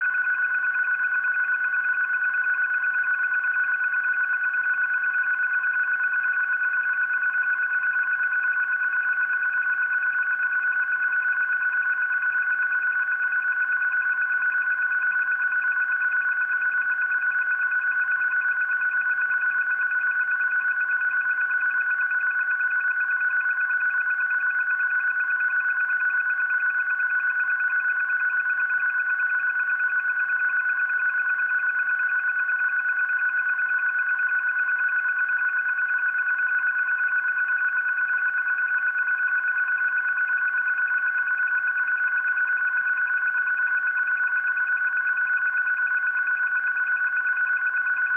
15.559MHz_USB.mp3